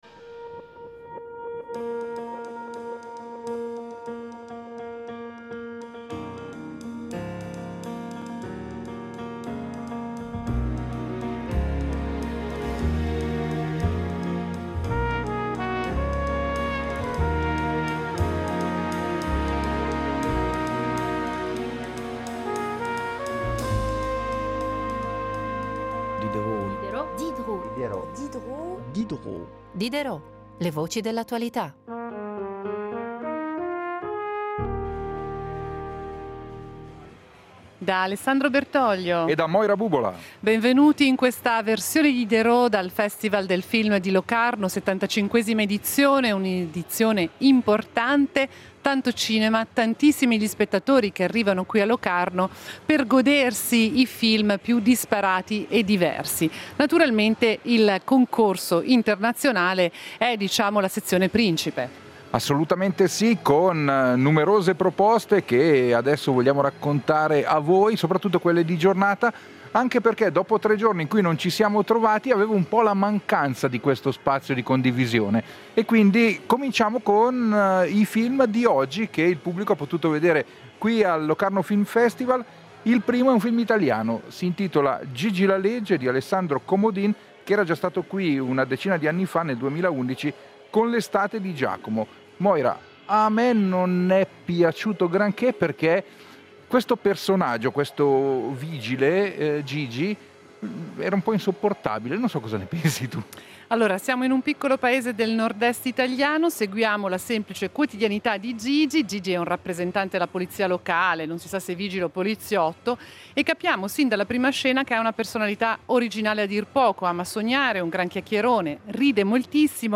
Diderot dedica la prima mezz’ora del programma al Locarno Film Festival , nei giorni in cui si svolge la sua 75ma edizione. In diretta dalla postazione RSI al Palacinema